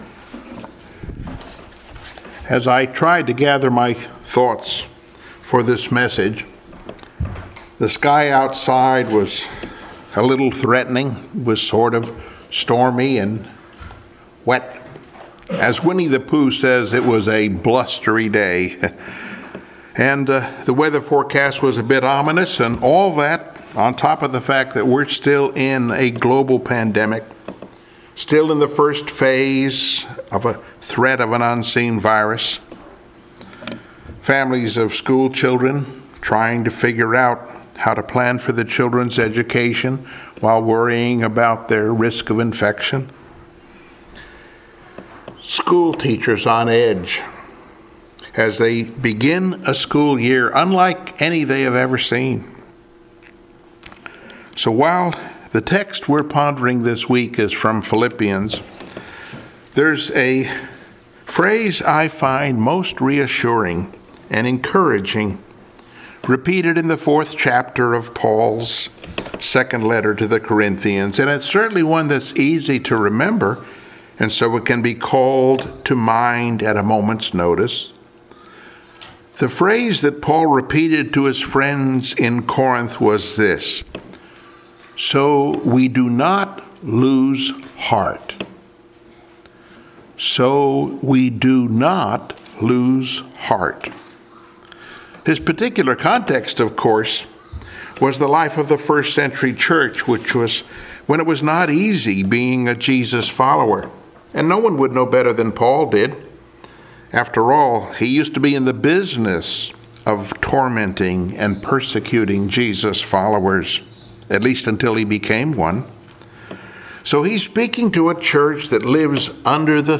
Passage: Philippians 4:6-7 Service Type: Sunday Morning Worship